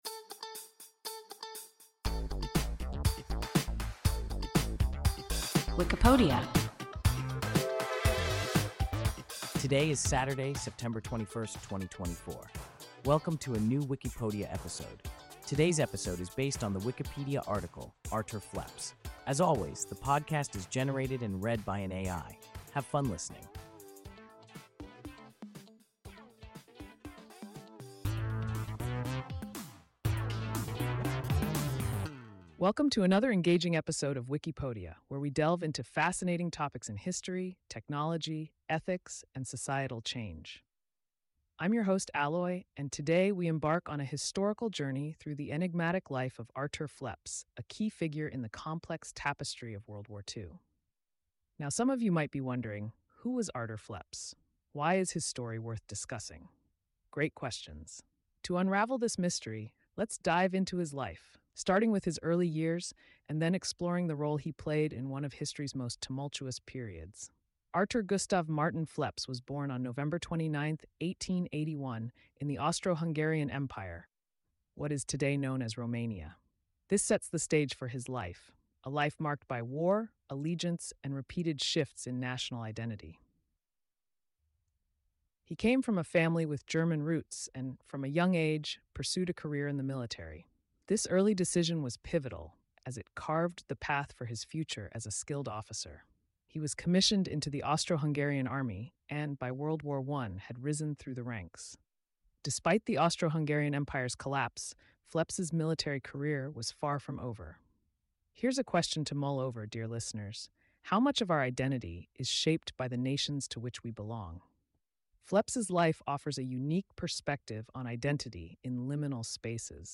Artur Phleps – WIKIPODIA – ein KI Podcast